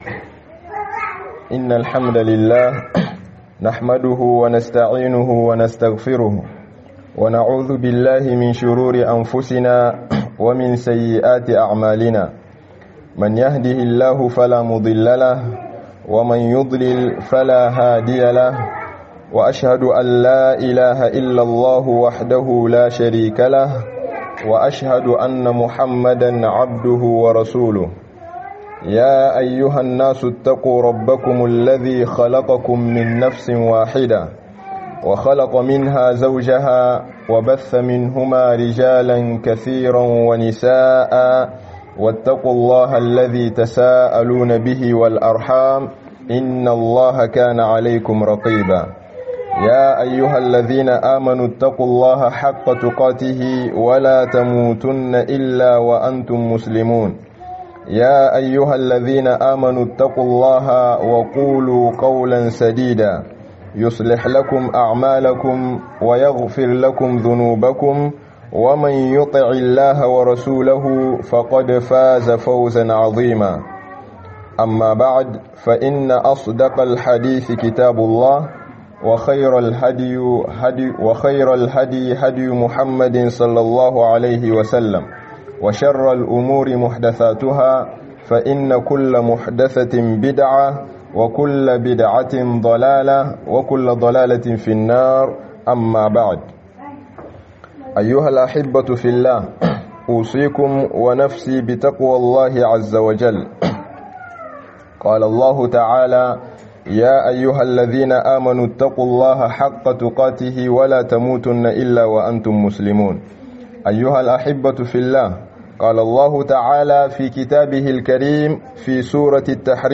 Ku Kare kanku da ƴaƴan ku daga wuta - Hudubobi